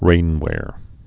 (rānwâr)